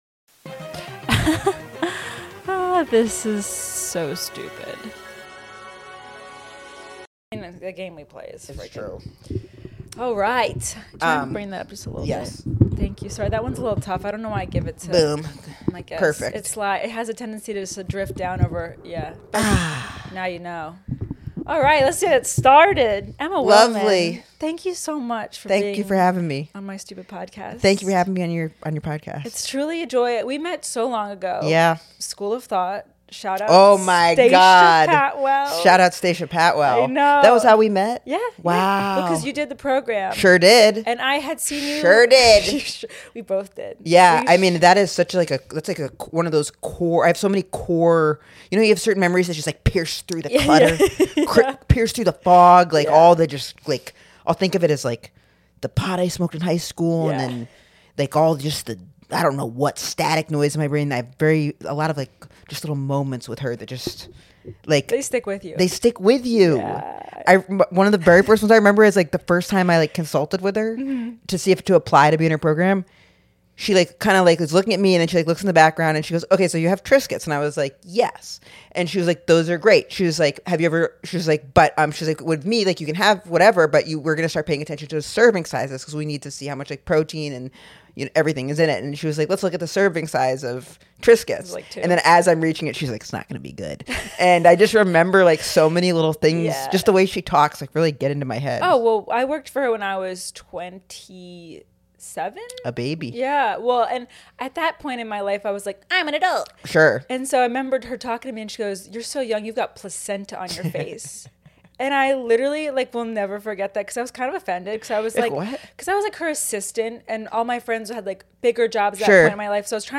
Comedy Interviews